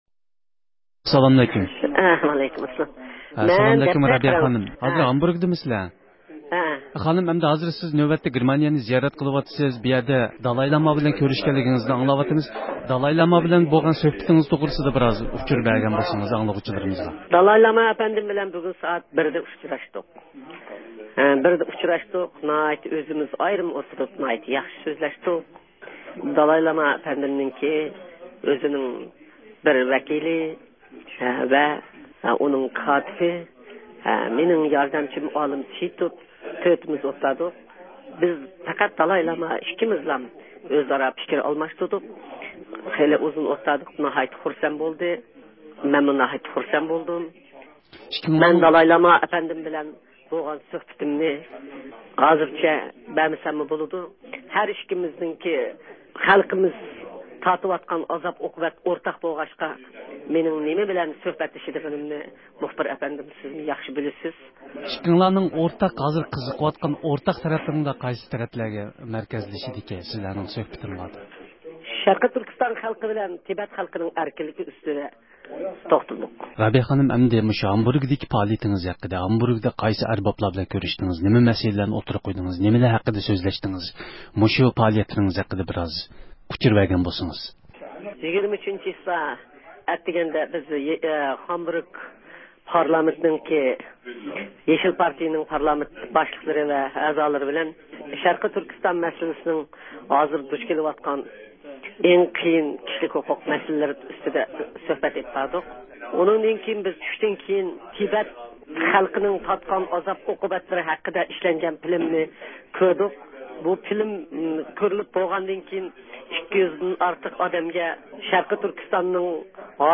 بىز رابىيە قادىر خانىمنىڭ گېرمانىيە سەپىرى ھەققىدە ئىلگىرلىگەن ئۇچۇرلارغا ئېرىشىش مەقسىتىدە ئۇنى زىيارەت قىلدۇق.